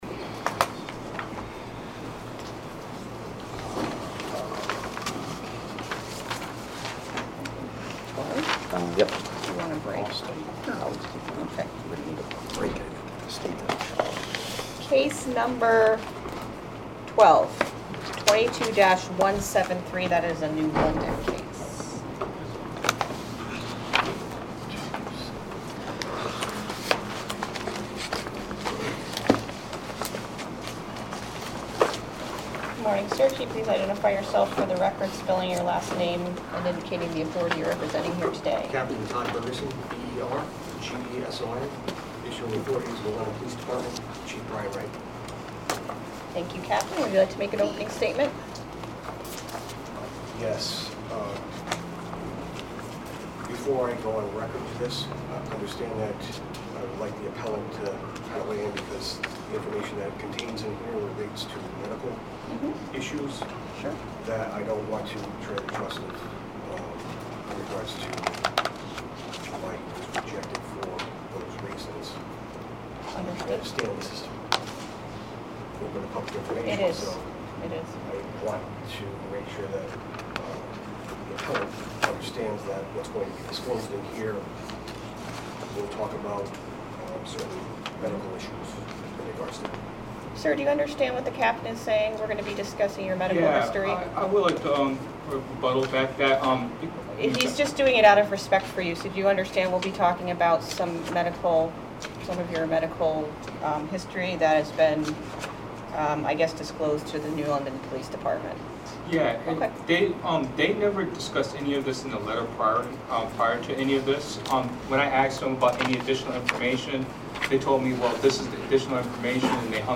Meeting of the Board of Firearms Permit Examiners